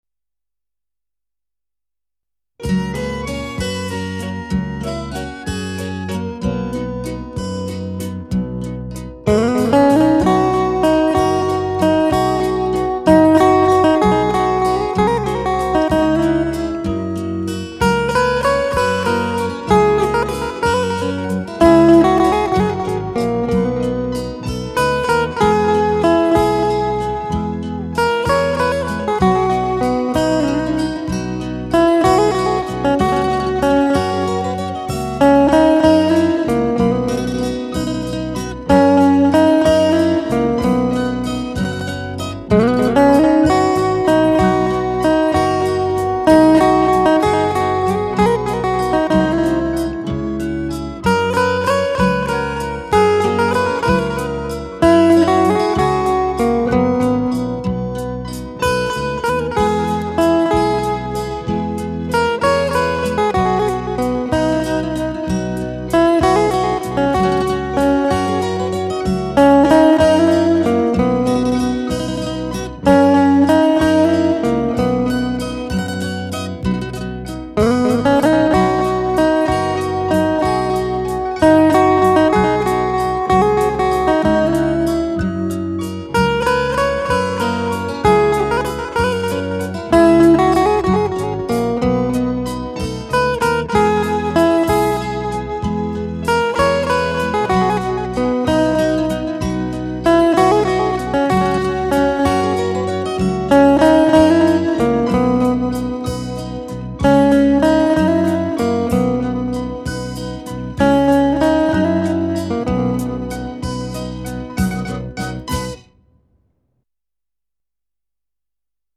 * Ca sĩ: Không lời
* Thể loại: Ngoại Quốc